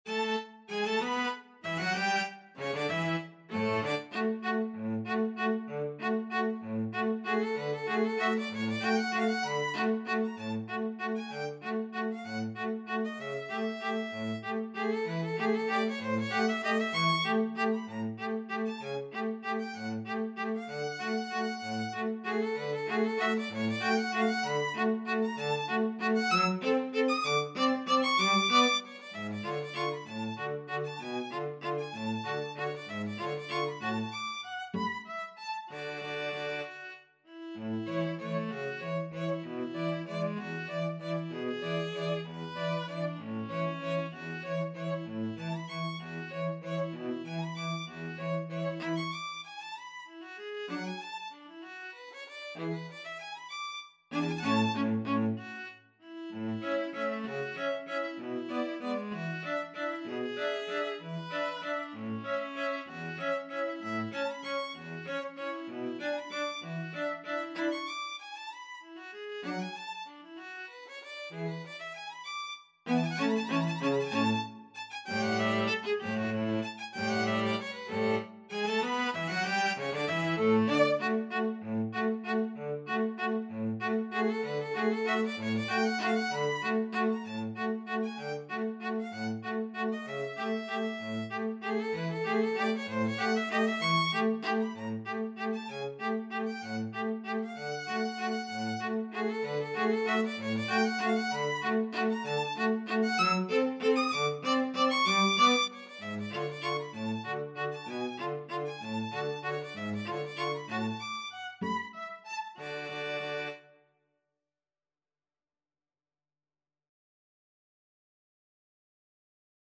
Tempo di Waltz (.=c.64)
Classical (View more Classical String trio Music)
String trio Sheet Music